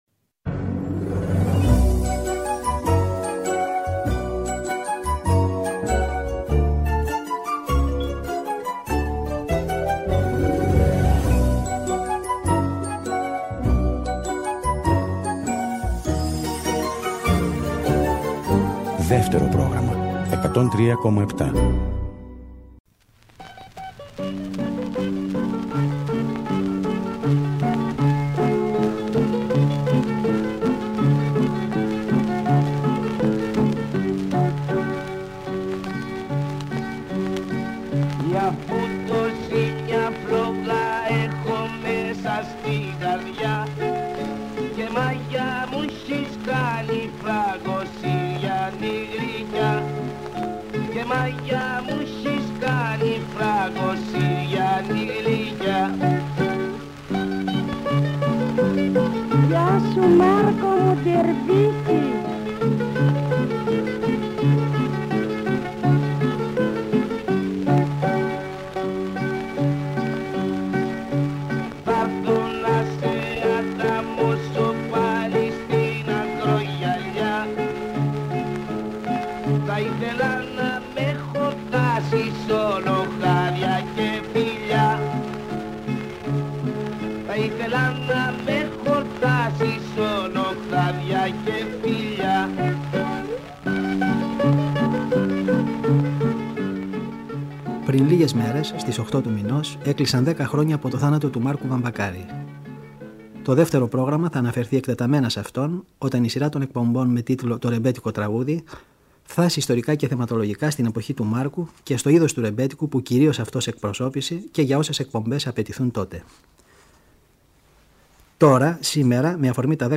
Ακούγονται προπολεμικά τραγούδια του από τις 78 στροφές